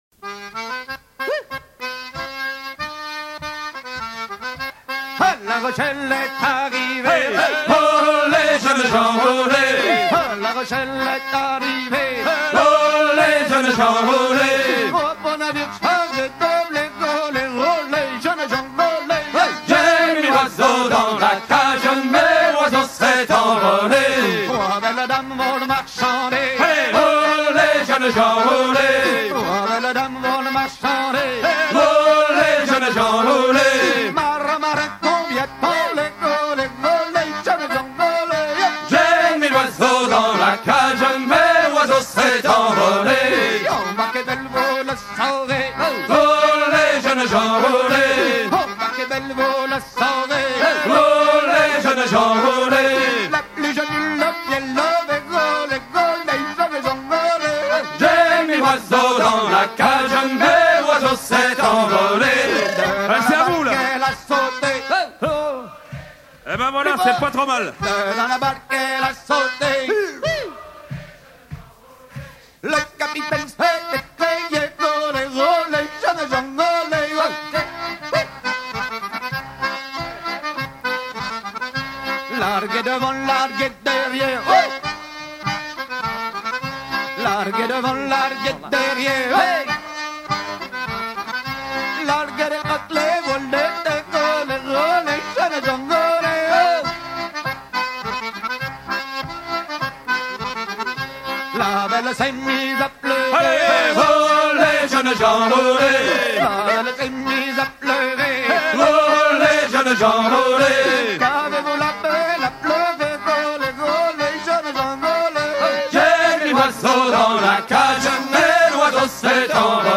chanson recueillie auprès d'un trre-neuva fécampois
Genre laisse